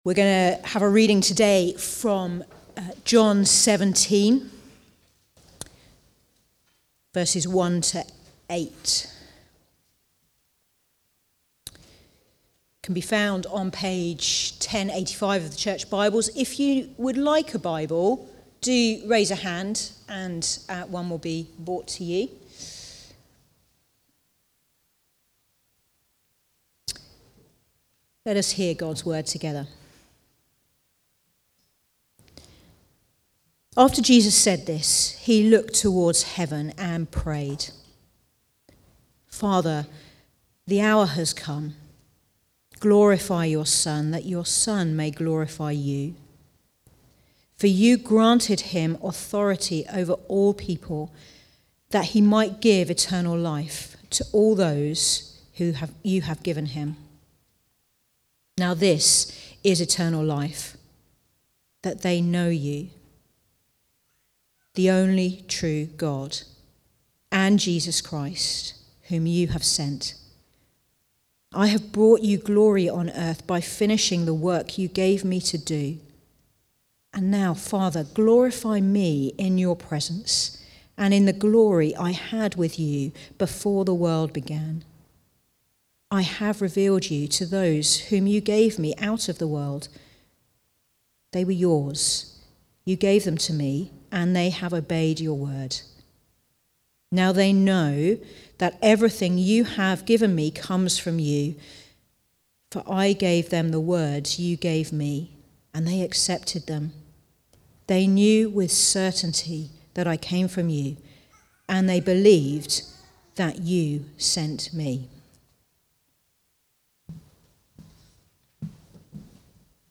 Preaching
The Glory (John 17:1-8) from the series Comfort and Joy. Recorded at Woodstock Road Baptist Church on 23 March 2025.